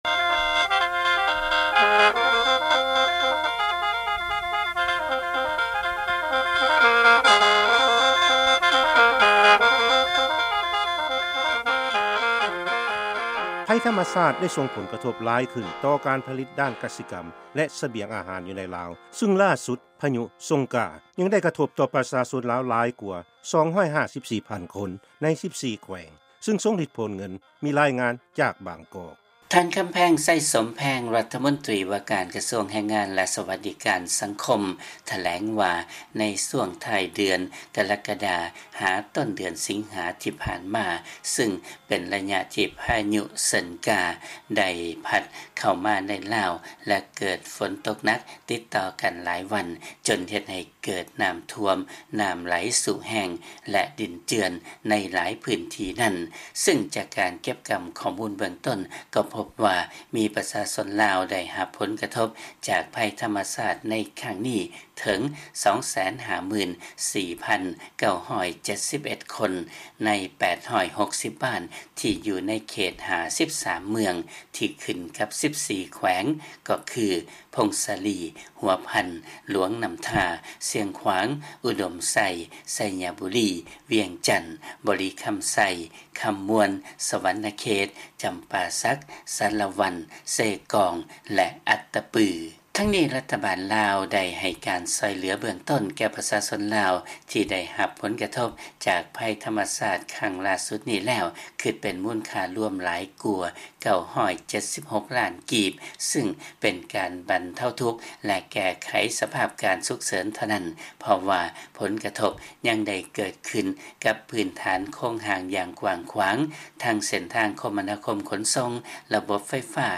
ເຊີນຟັງລາຍງານເລື້ອງຜົນກະທົບຂອງໄພພິບັດທໍາມະຊາດ